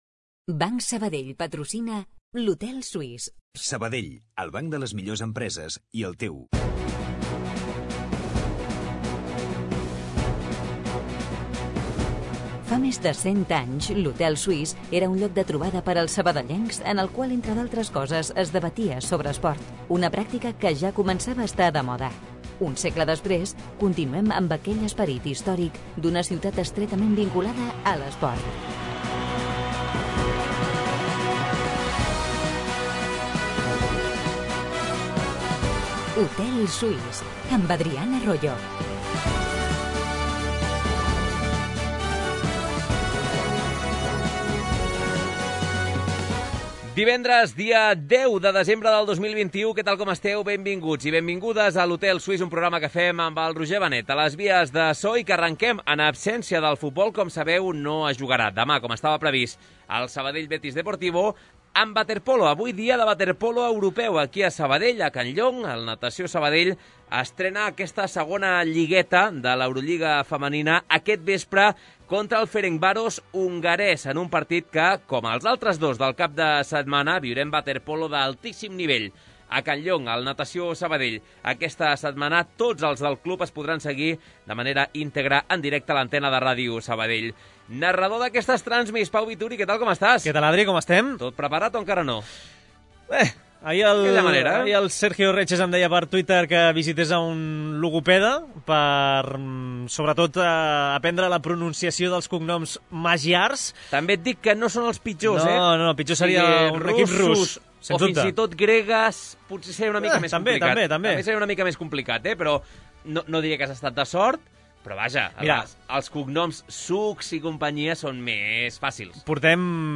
Les tertúlies esportives del recordat Hotel Suís de Sabadell prenen forma de programa de ràdio. Com passava llavors, l'hotel es converteix en l'espai reservat per a la reflexió, el debat i la conversa al voltant de l'esport de la ciutat.